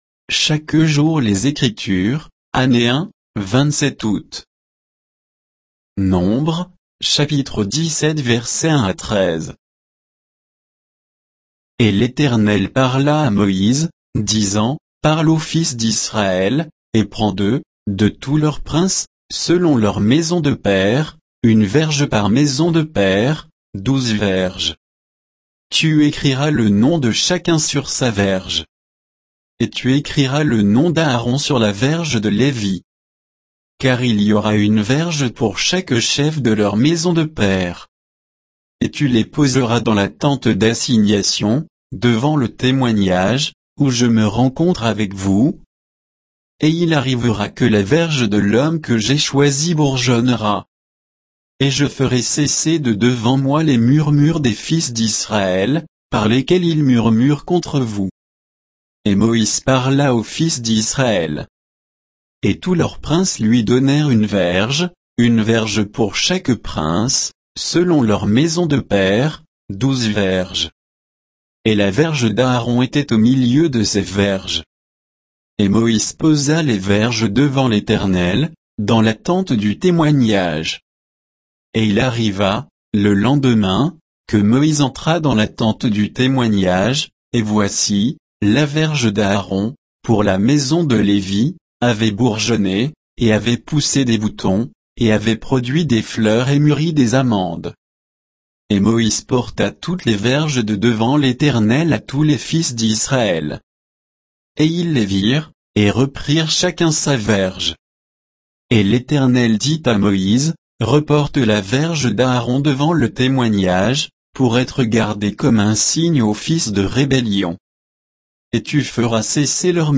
Méditation quoditienne de Chaque jour les Écritures sur Nombres 17